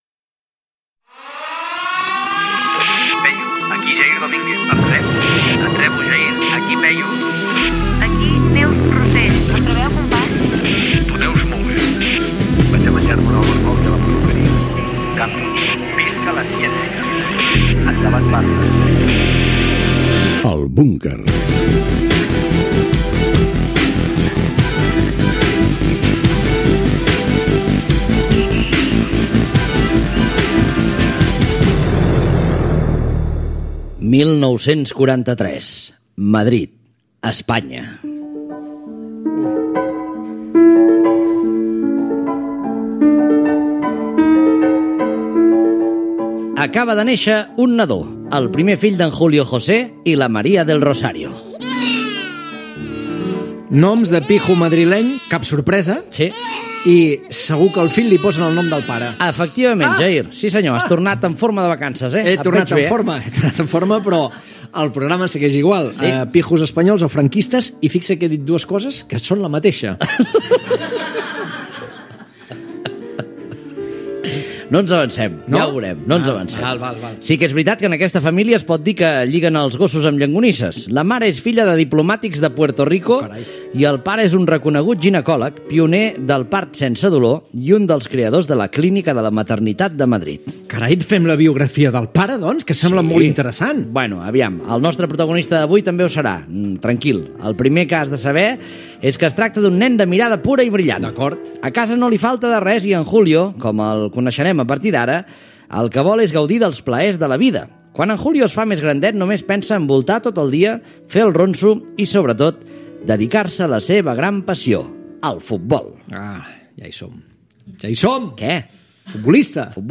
Careta del programa, presentació i espai dedicat a la biografia humorística dels primers anys del cantant Julio Iglesias.
Entreteniment